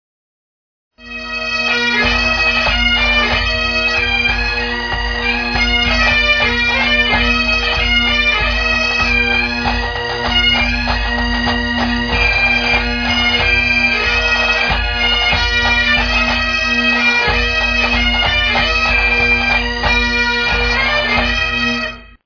Pipes & Drums
Audio samples are low resolution for browsing speed.
Original Recordings:  Audio Arts Studio, Johannesburg 1984